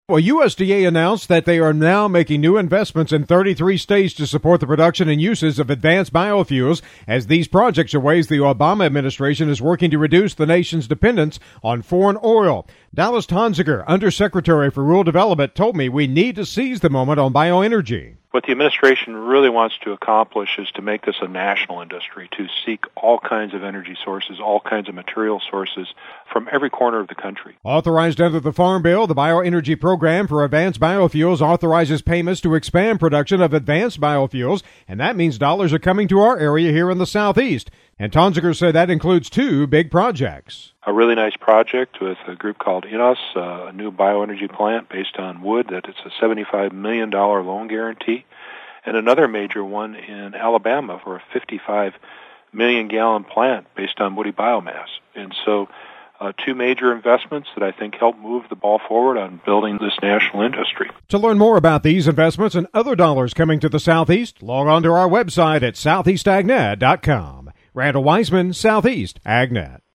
Dallas Tonsager, Under Secretary for Rural Development, told me that dollars are coming to the Southeast for various projects.